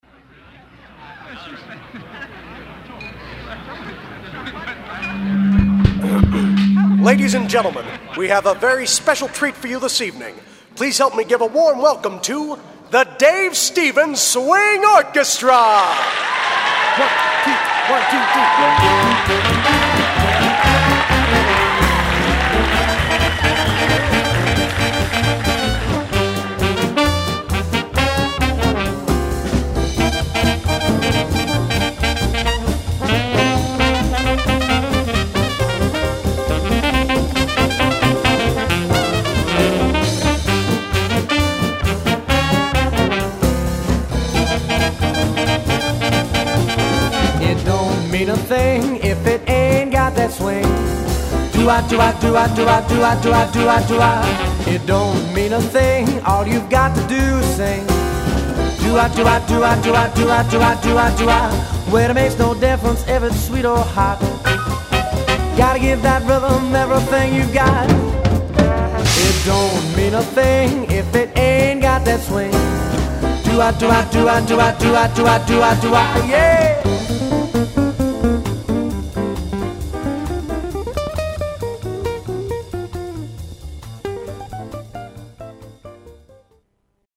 SWING & BIG BAND